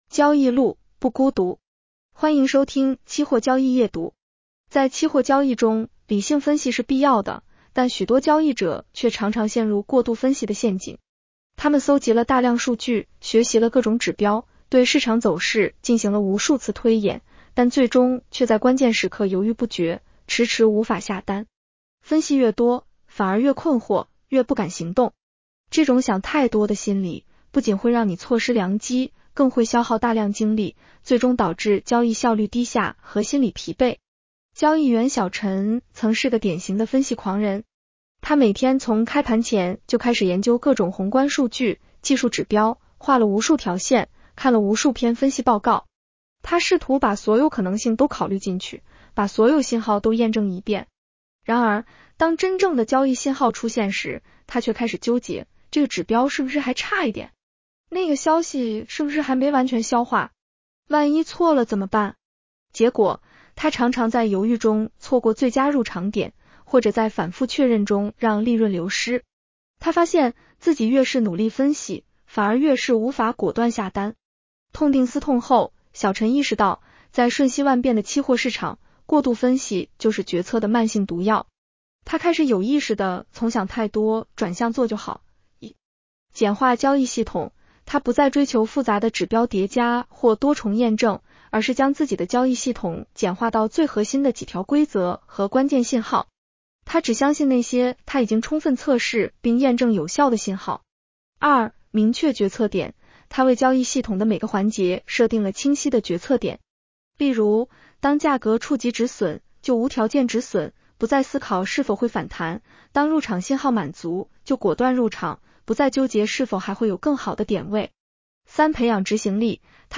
女声普通话版 下载mp3